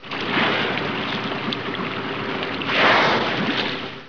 Would you like to hear a Gray Whale breathing ?
whaleair.wav